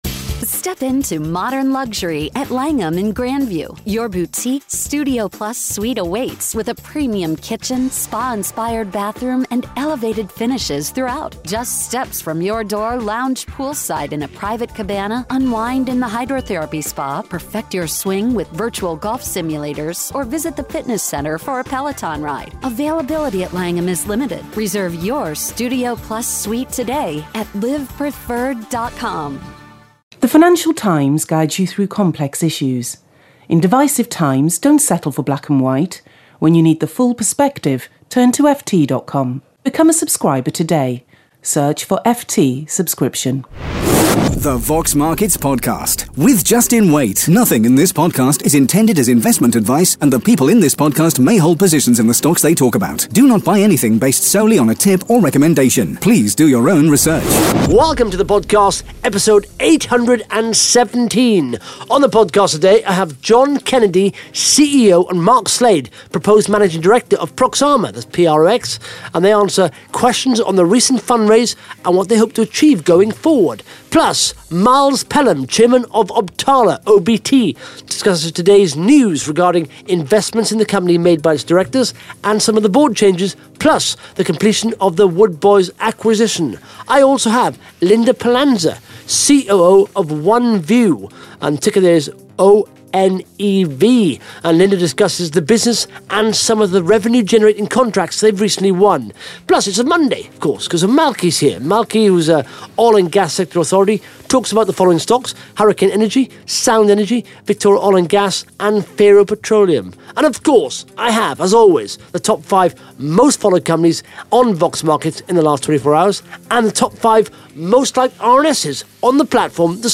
(Interview starts at 1 minute 30 seconds)